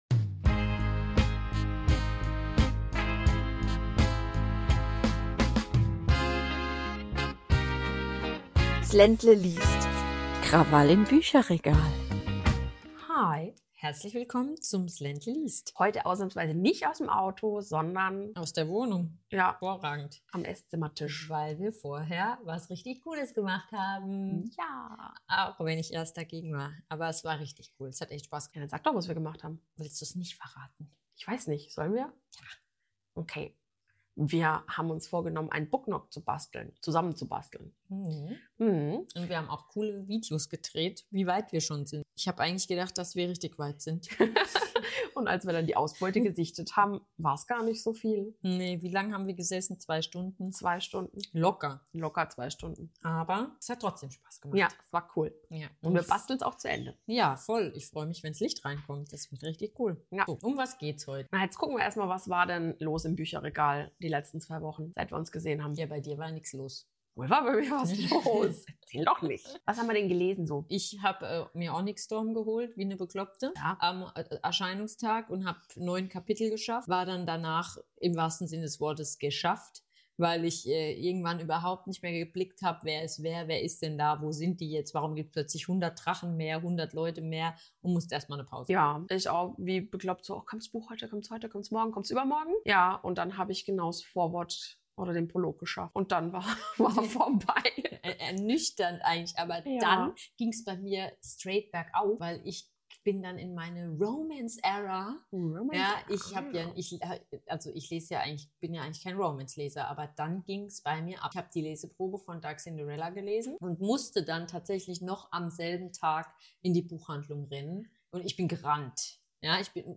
Wir – zwei begeisterte Buchliebhaberinnen – sprechen über die spannendsten Neuerscheinungen des Jahres. Welche Romane stehen schon auf unserer Must-Read-Liste?